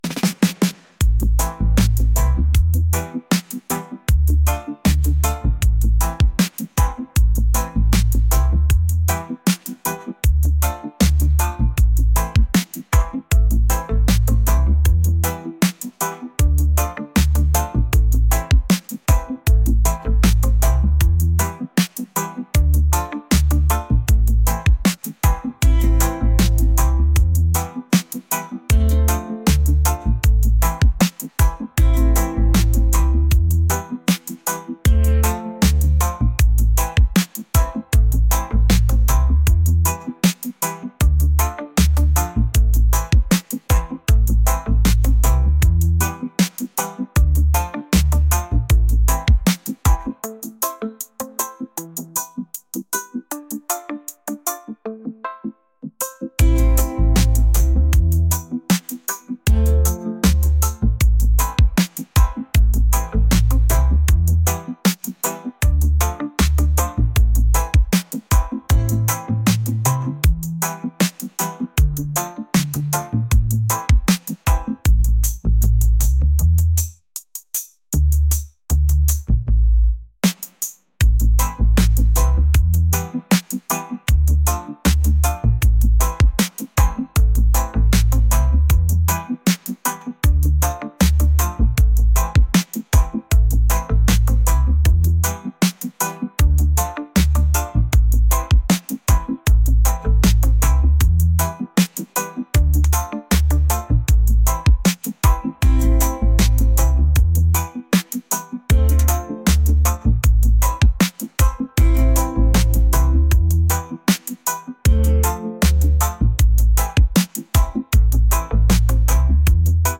reggae | soul & rnb